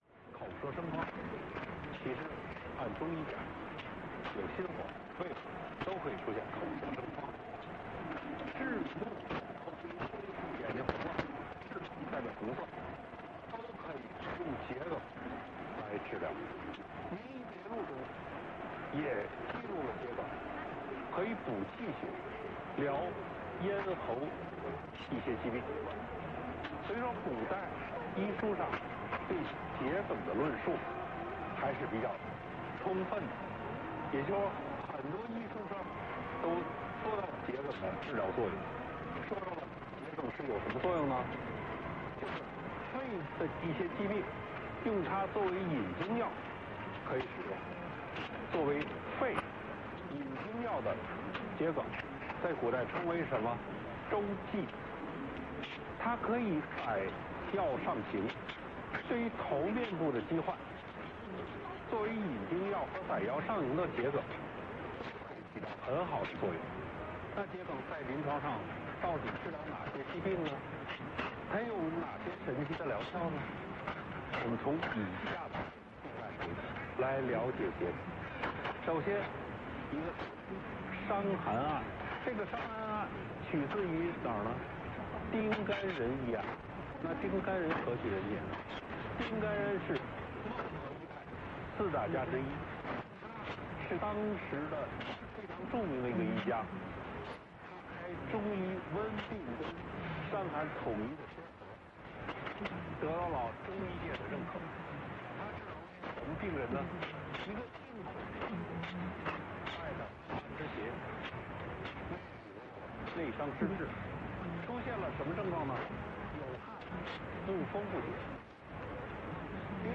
720kHzで中国郷村之声を受信できました。
良好だったので『健康到家』終了部分を書き起こしてみました。
しっかりと局名が出ています。
<受信地:岩手県 RX:SIHUADON D-808>